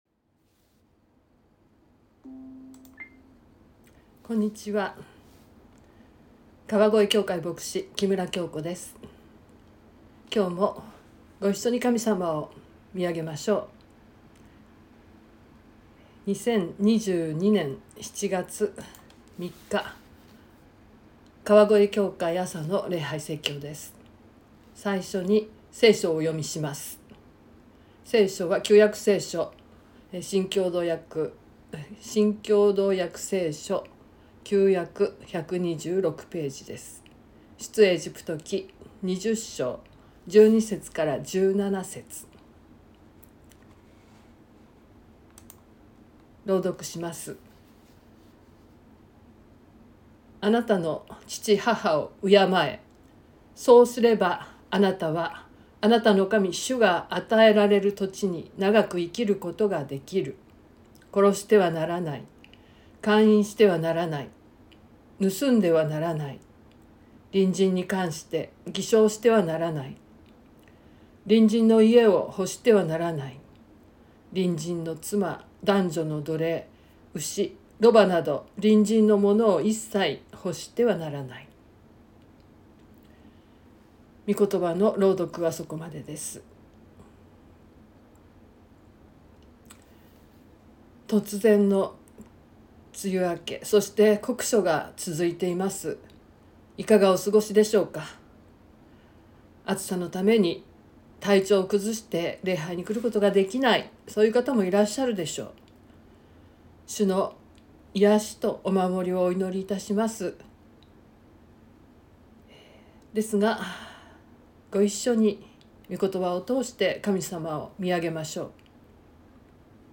2022年07月03日朝の礼拝「なぜ不倫はいけないのか」川越教会
説教アーカイブ。
音声ファイル 礼拝説教を録音した音声ファイルを公開しています。